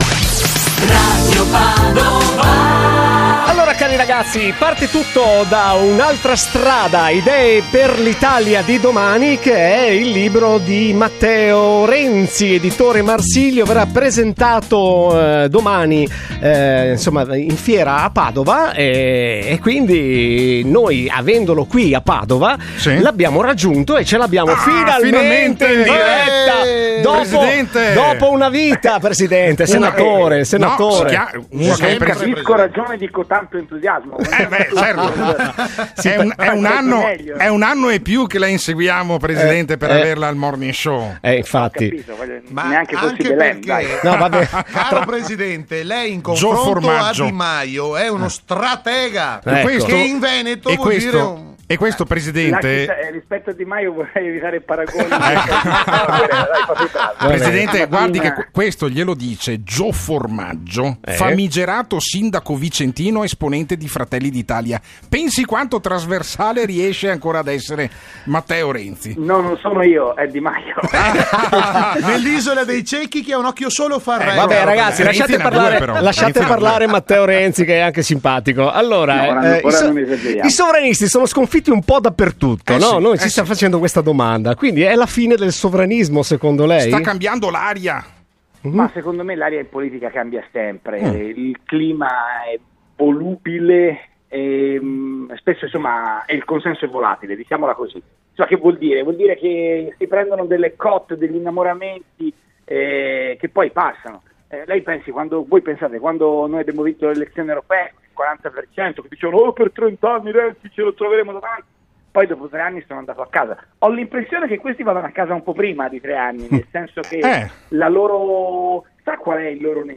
Lo ha detto Matteo Renzi, ex presidente del Consiglio, intervenendo telefonicamente questa mattina al Morning Show di Radio Padova.